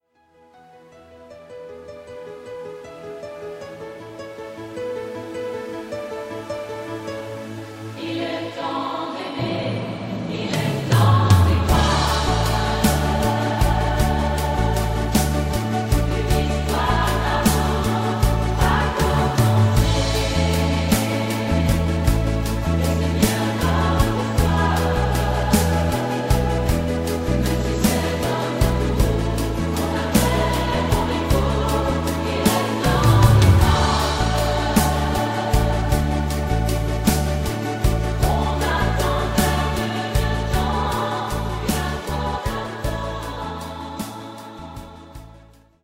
avec choeurs originaux